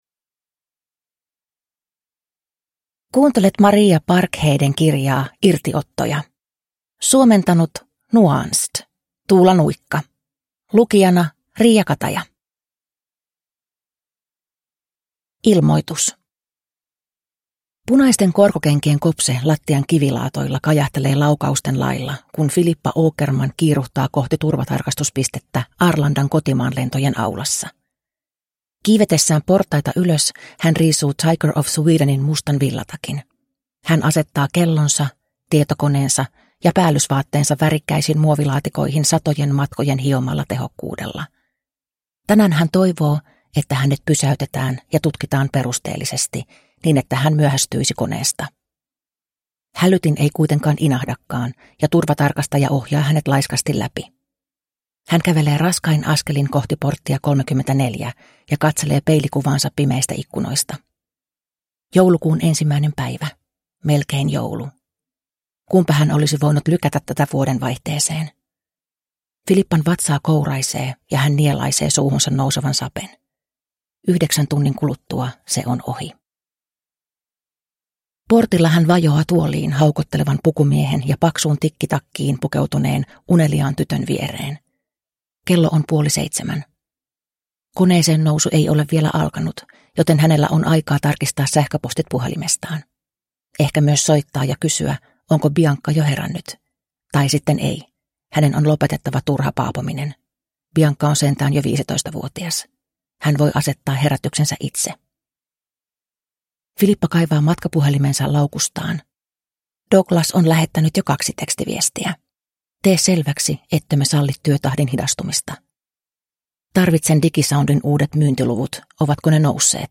Irtiottoja – Ljudbok – Laddas ner